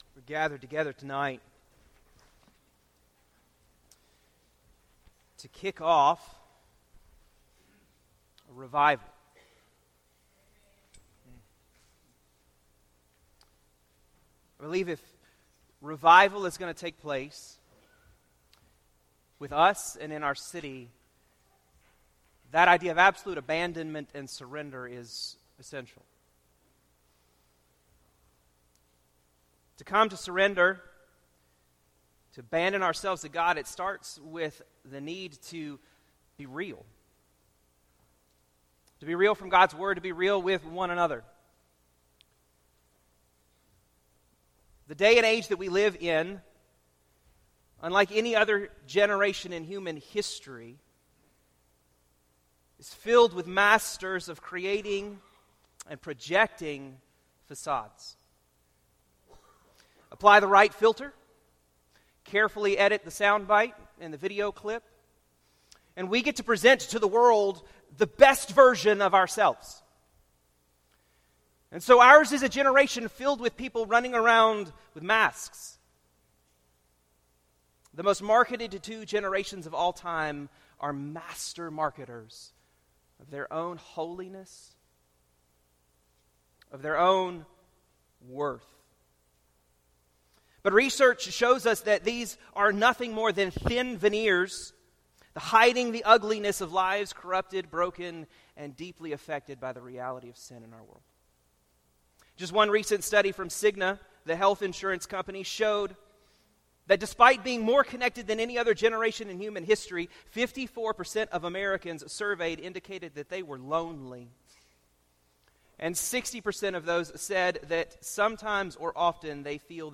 This sermon was delivered during the opening night of the County-wide Interdenominational Revival. Religiosity is not a cover for rebellion or substitute for righteousness.